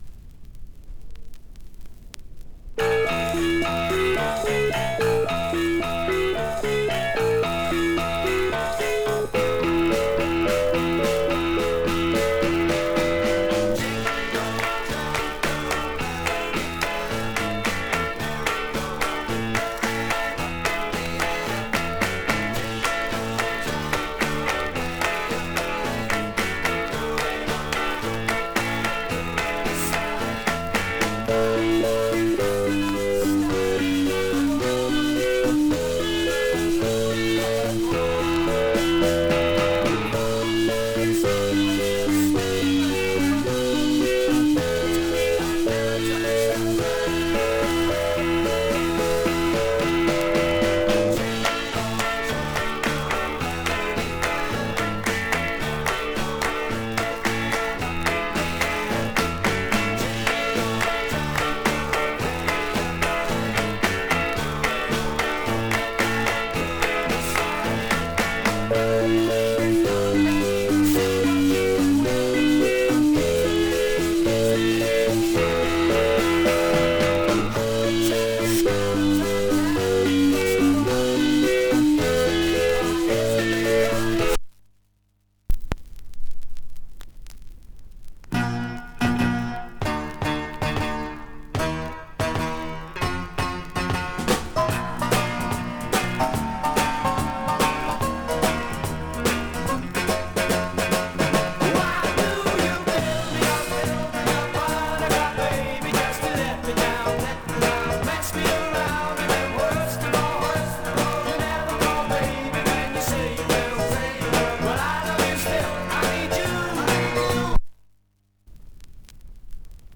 Жанр: PSYCH
Есть потертости и поверхностные царапинки, иногда слышные.